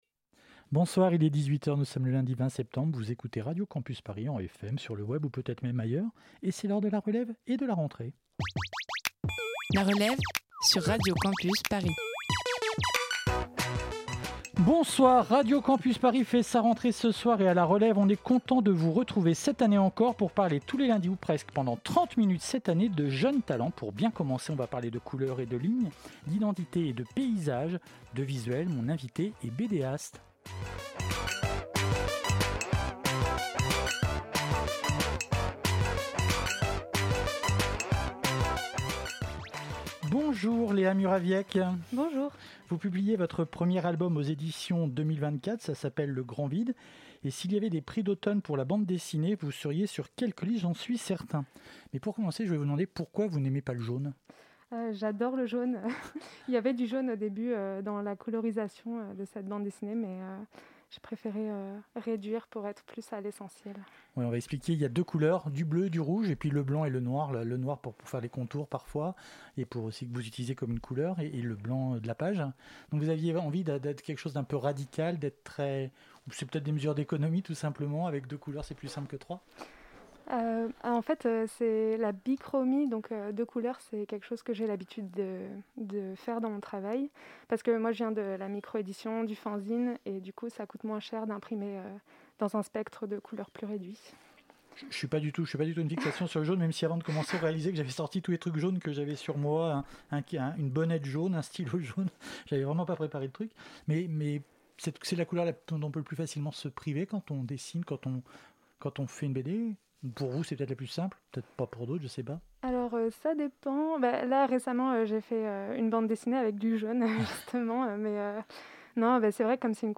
L'archive diffusée pendant l'émission provient de ce programme de France 24